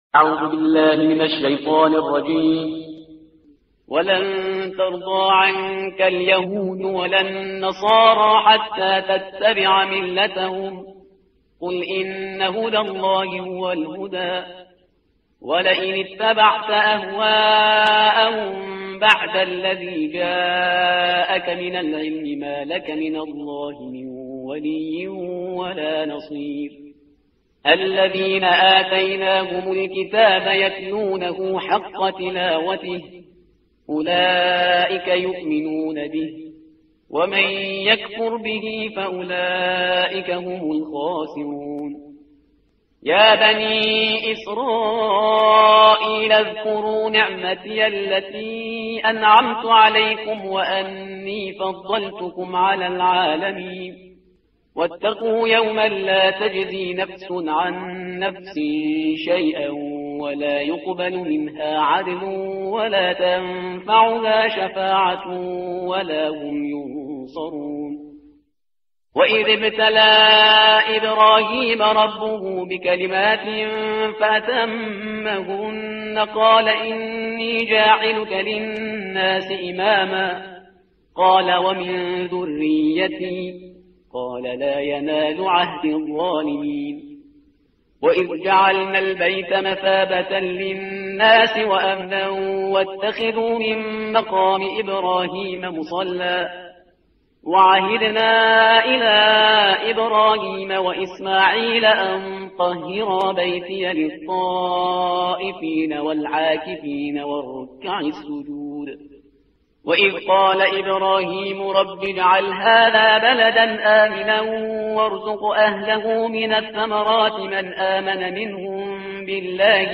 ترتیل صفحه 19 قرآن با صدای شهریار پرهیزگار